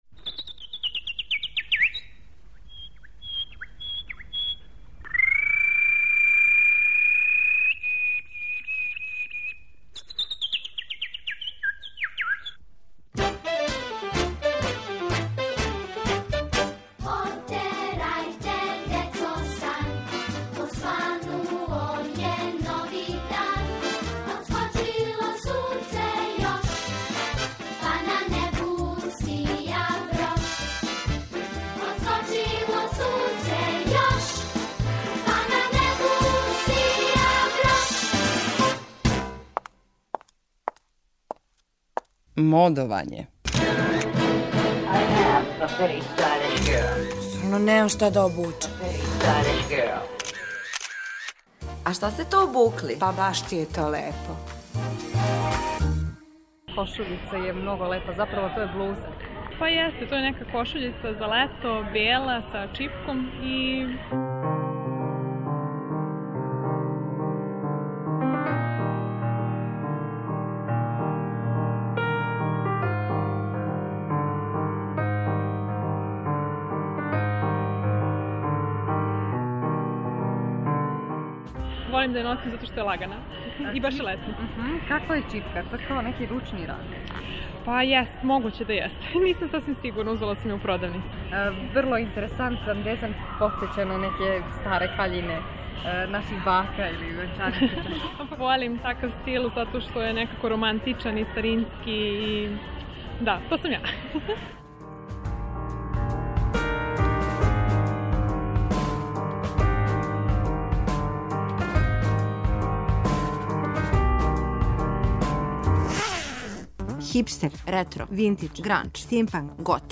У серијалу МОДОВАЊЕ питамо занимљиво одевене случајне пролазнике да опишу своју одевну комбинацију.